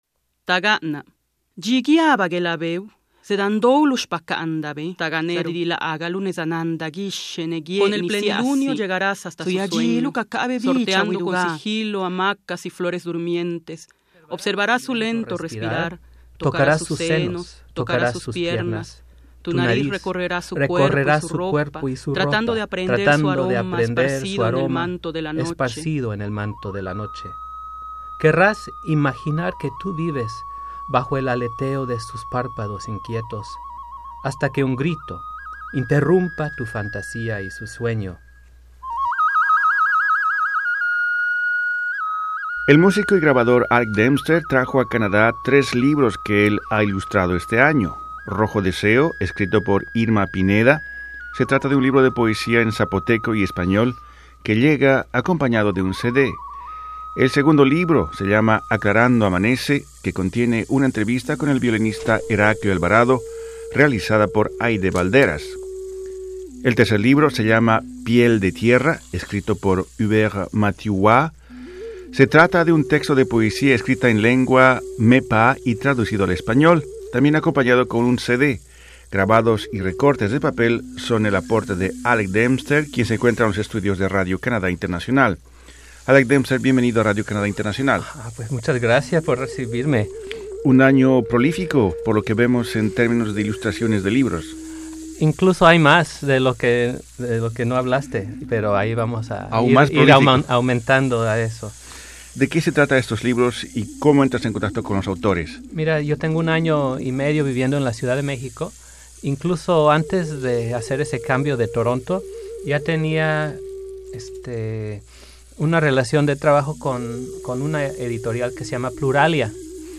En conversación con Radio Canadá Internacional